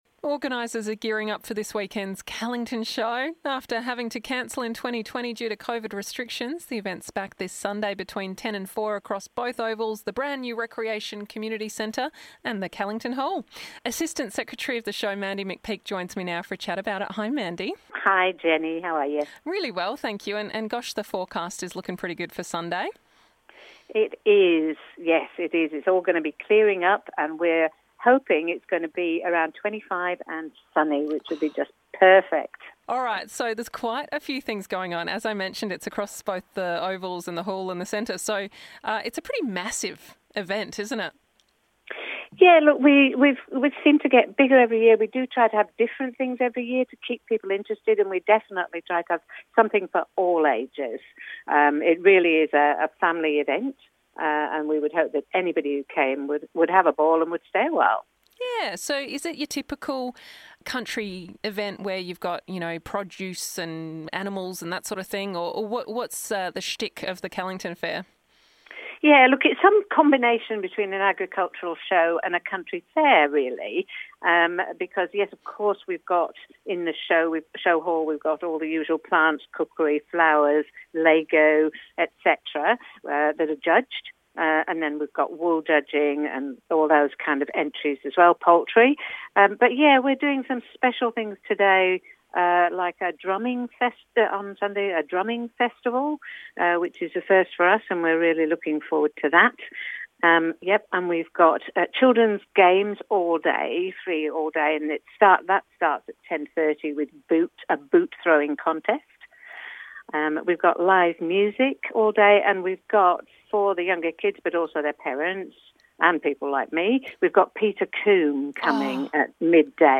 for a chat about it.